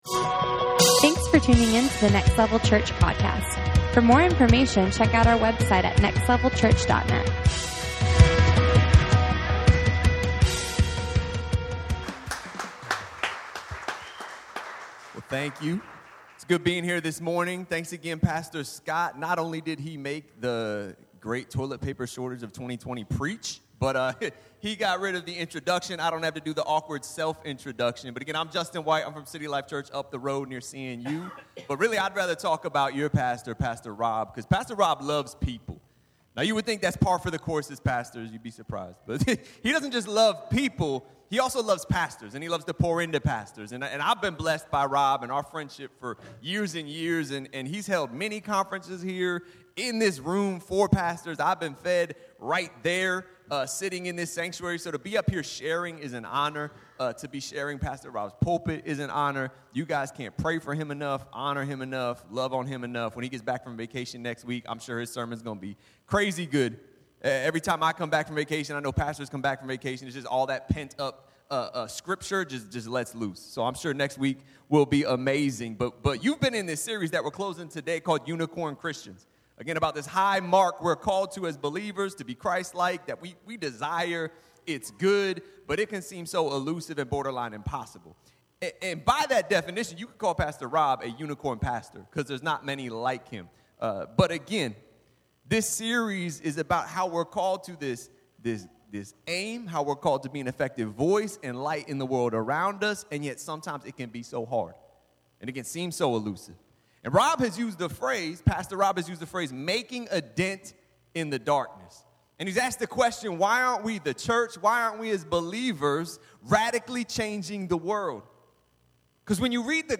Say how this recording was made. Unicorn Christians Service Type: Sunday Morning Watch A unicorn is a mythical creature.